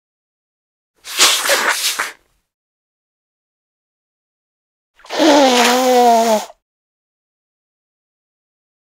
Tiếng Xì mũi, Hỉ mũi nước (đàn ông)
Thể loại: Tiếng hoạt động con người
Description: Hiệu ứng tái hiện một cách chân thực và sống động âm thanh của hành động xì mũi mạnh, hỉ mũi có nước, hay còn gọi là xì nước mũi, khịt mũi, xì hơi mũi, xì ra từ mũi, hoặc xì mũi khi bị cảm lạnh.
tieng-xi-mui-hi-mui-nuoc-dan-ong-www_tiengdong_com.mp3